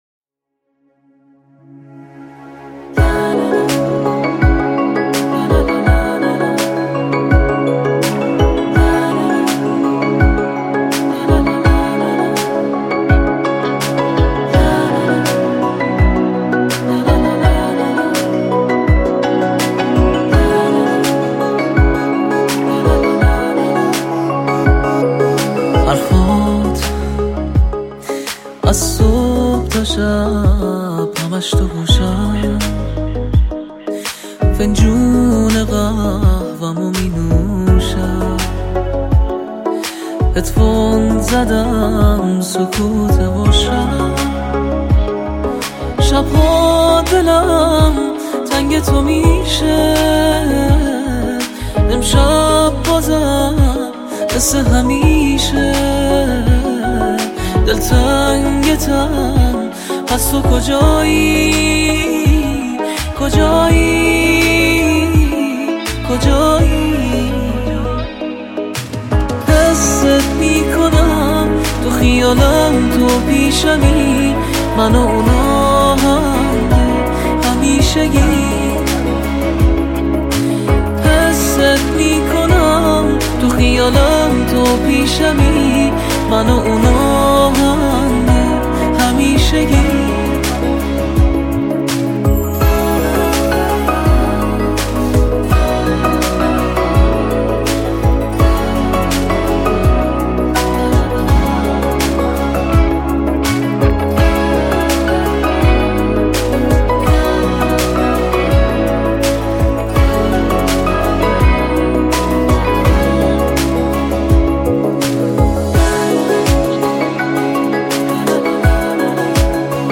با ریتم 6/8